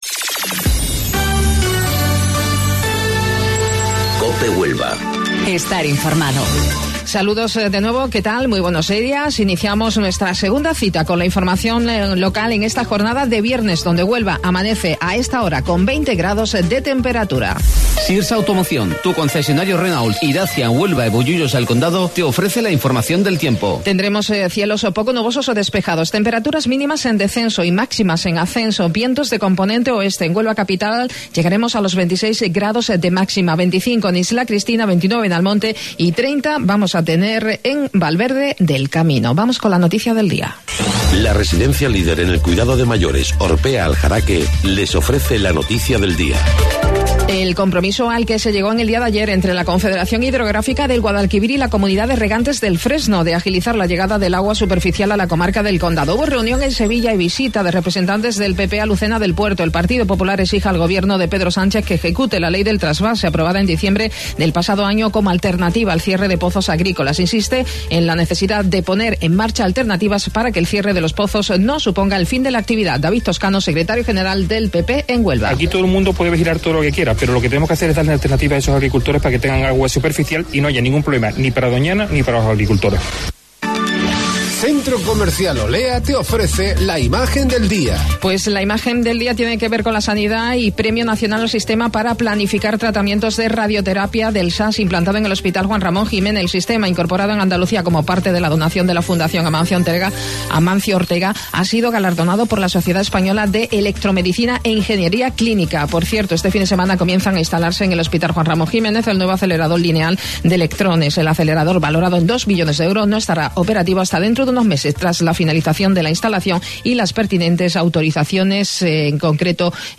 AUDIO: Informativo Local 08:25 del 5 de Julio